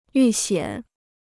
遇险 (yù xiǎn) Dictionnaire chinois gratuit